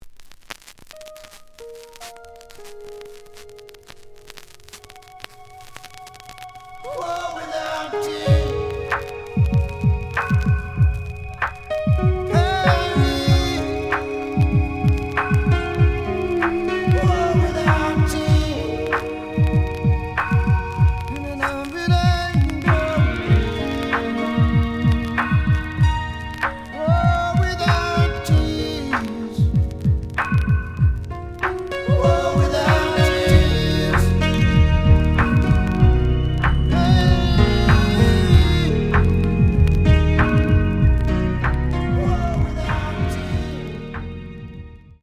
The audio sample is recorded from the actual item.
●Genre: Rock / Pop